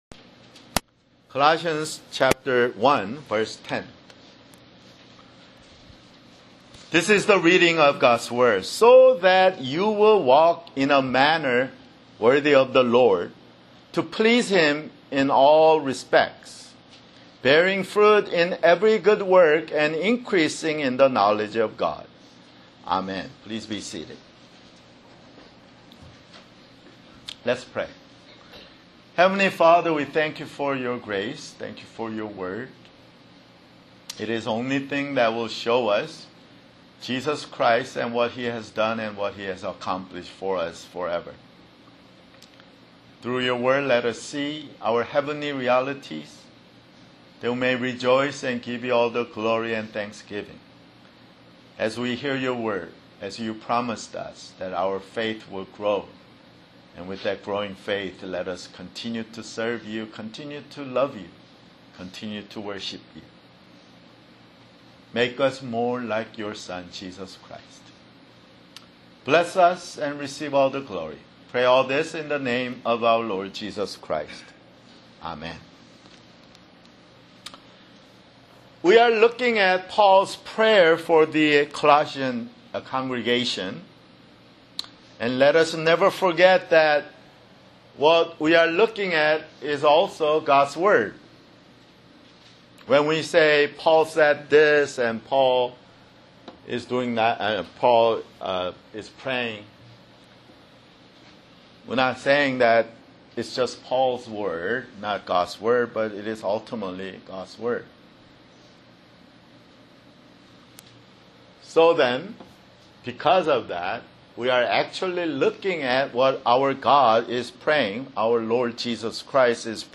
[Sermon] Colossians (18)